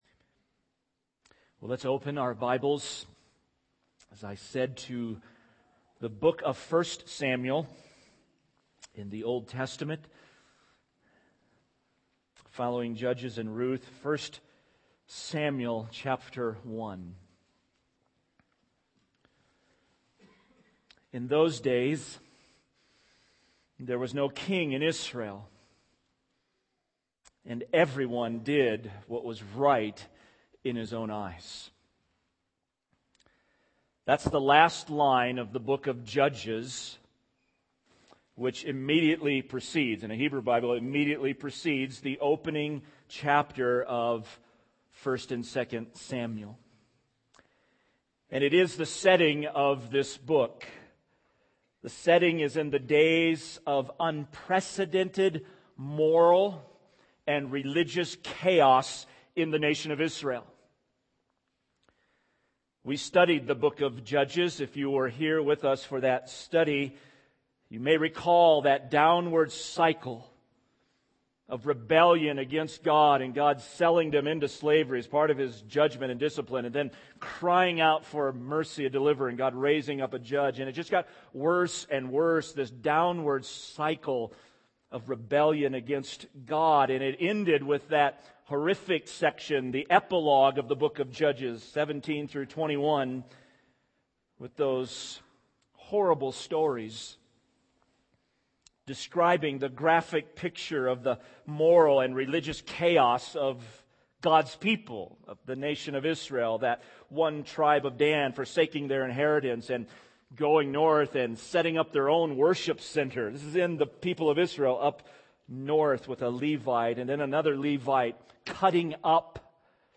SERMON – Crossroads Bible Church